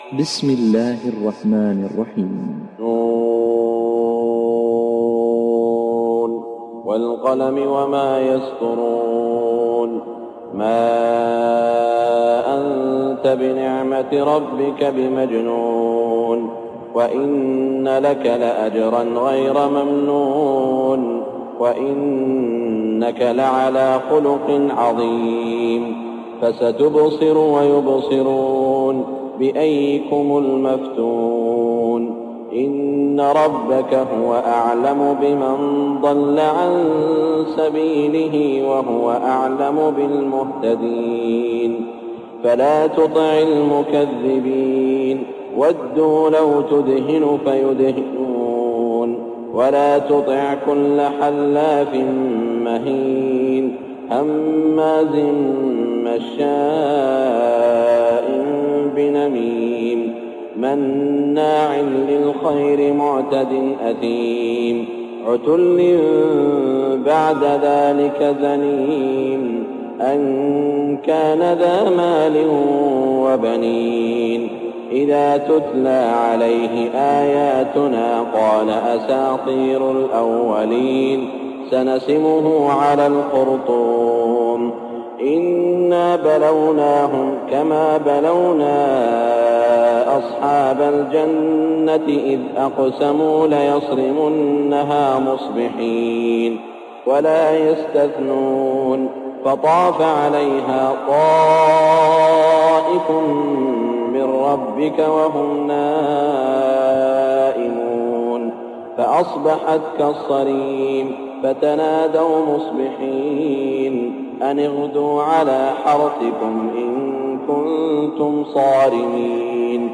صلاة الفجر 1418هـ من سورة القلم > 1418 🕋 > الفروض - تلاوات الحرمين